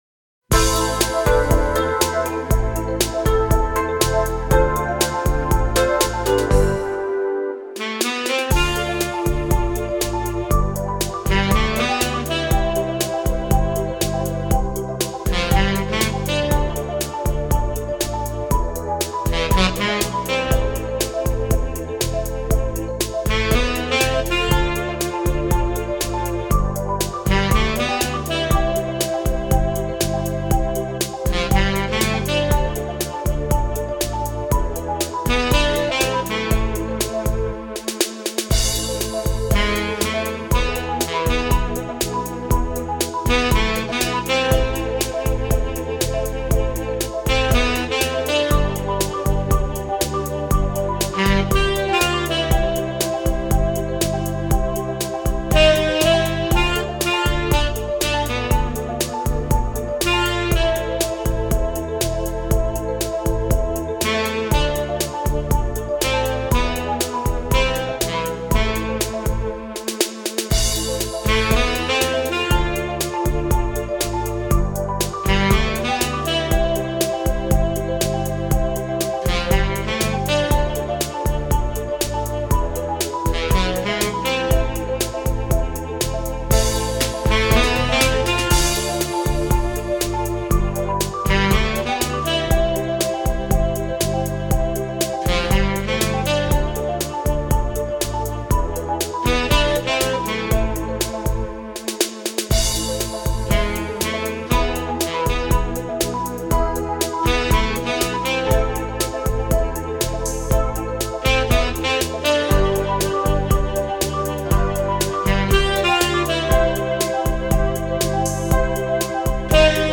电子琴
本专辑是根据世界名曲节奏改编，吸取西方爵士的演奏风格，是电子琴演奏领域的新尝试，希望您的认可与喜欢。